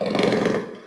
sounds_leopard_saw_03.ogg